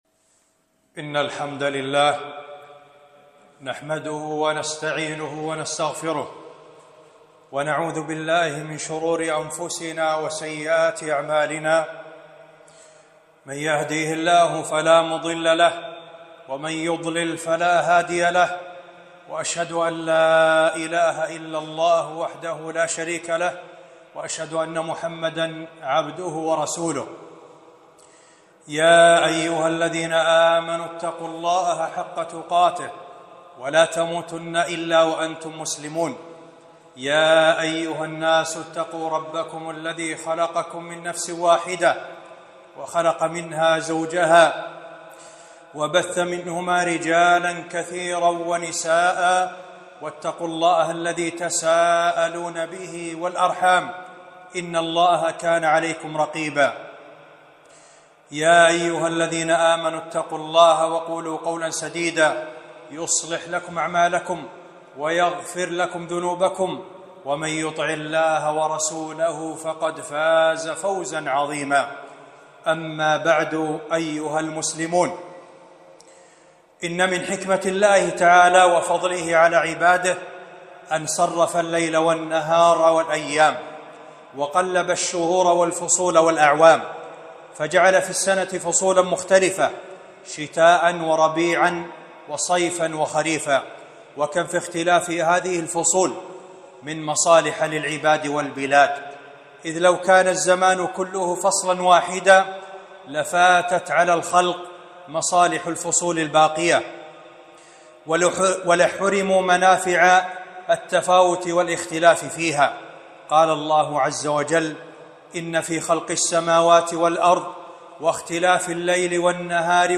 خطبة - الشتاء فضائل وآداب وأحكام